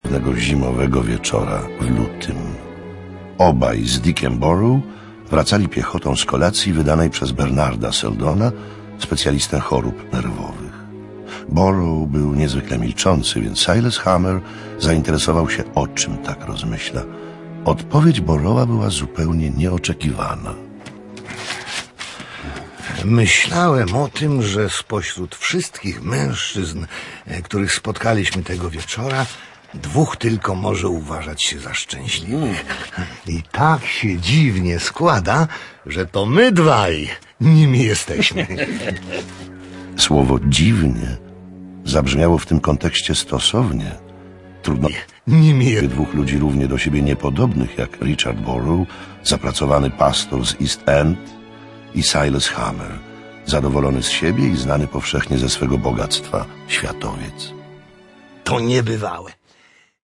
Sluchowisko na plycie CD.